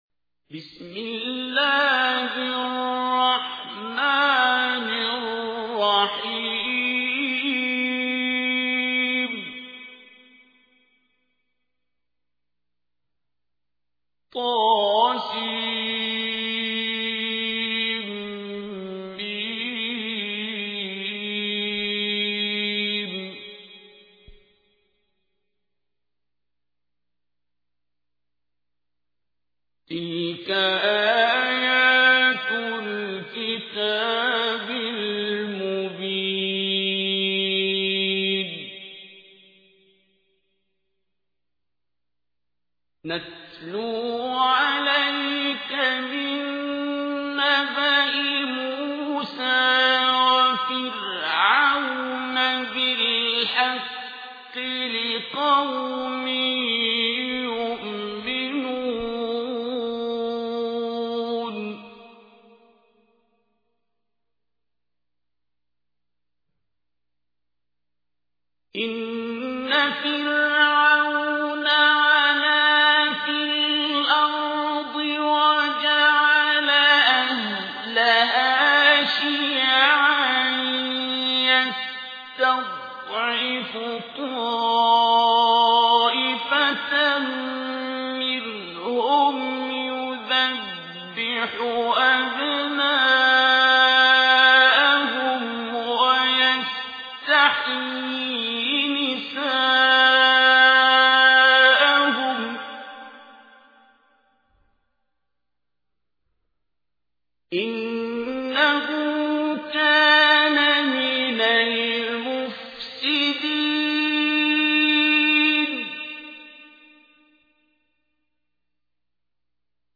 تحميل : 28. سورة القصص / القارئ عبد الباسط عبد الصمد / القرآن الكريم / موقع يا حسين